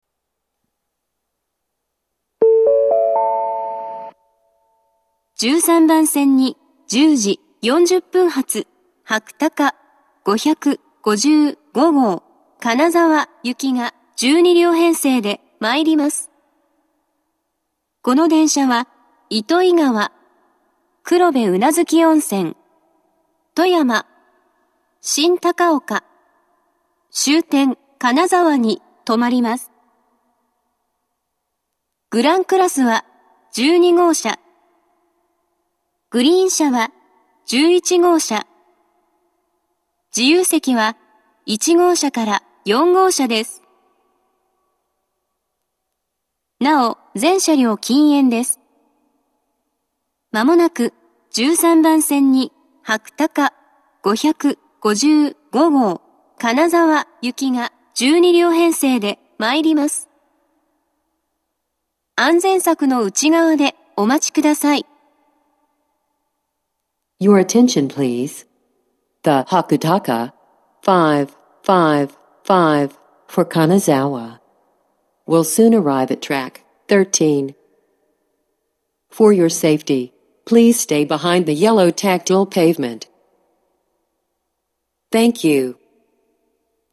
１３番線接近放送